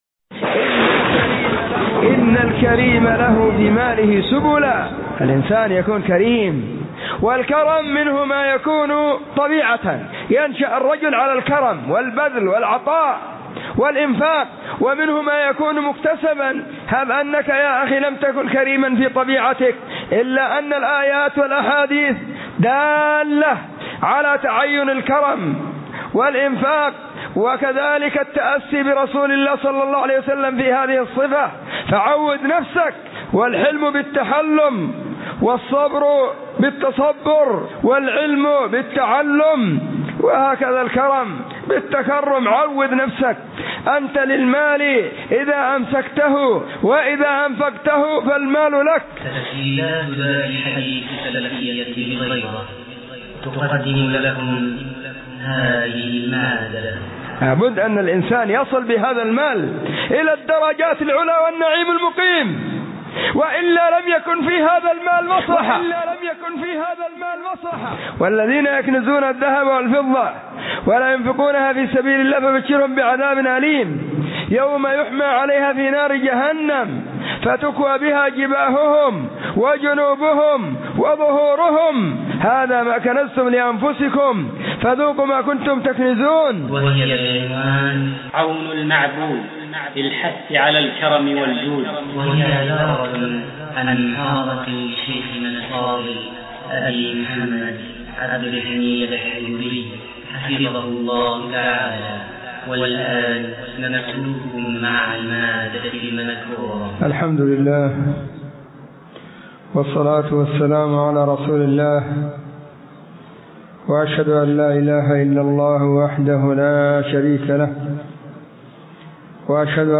محاضرة بعنوان :عون المعبود في الحث على الكرم والجود*
📢 مسجد الصحابة – بالغيضة – المهرة، اليمن حرسها الله،